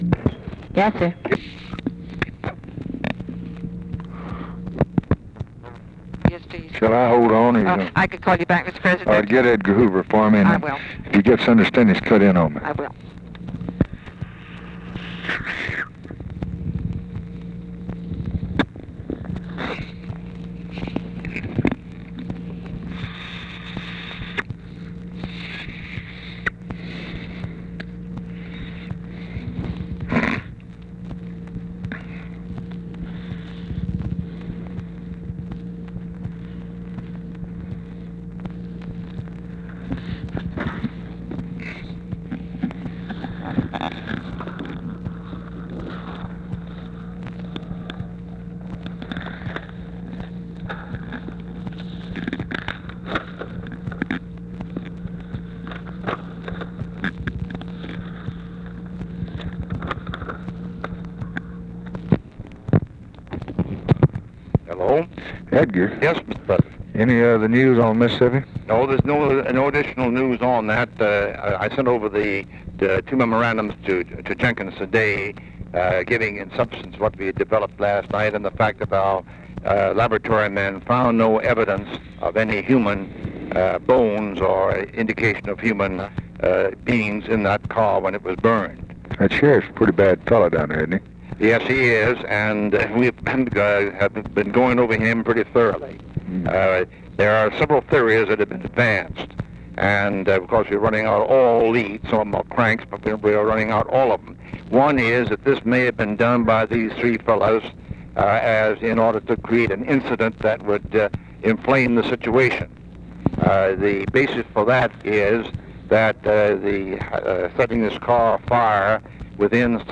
Conversation with J. EDGAR HOOVER, June 24, 1964
Secret White House Tapes